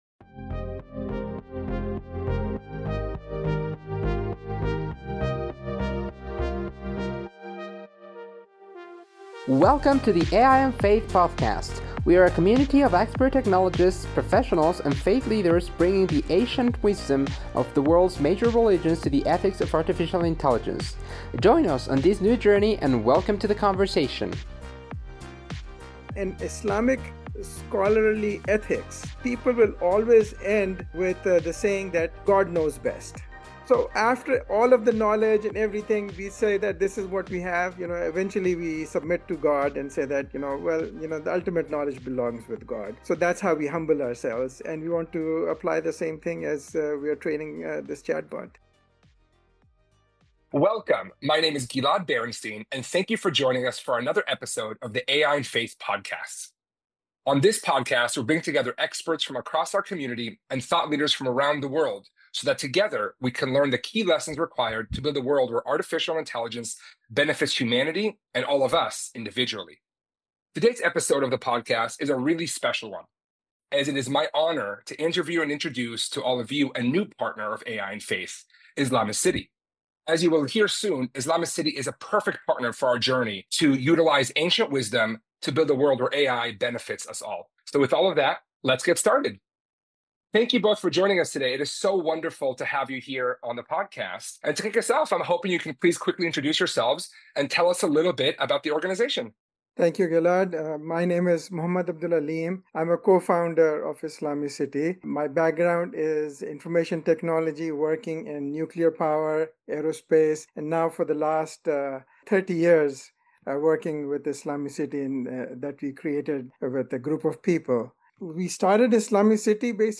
In this conversation, we welcome one of AI and Faith’s newest partners to our community.